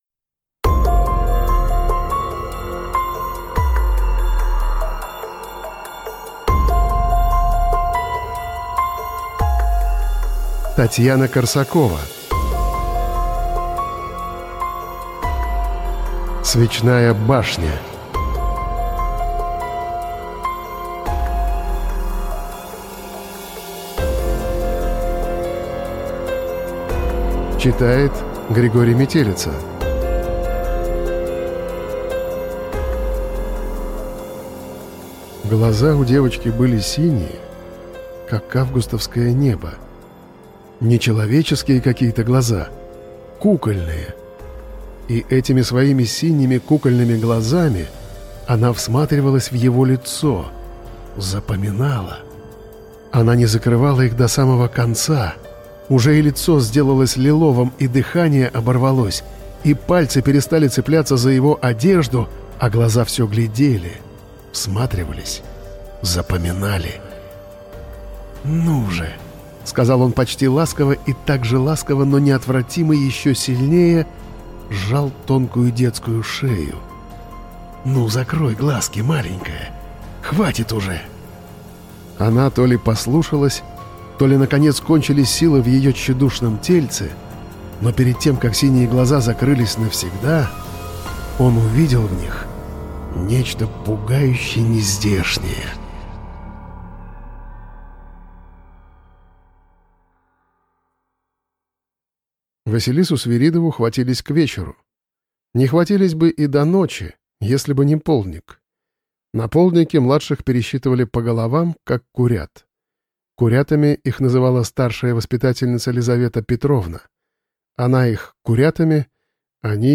Аудиокнига Свечная башня | Библиотека аудиокниг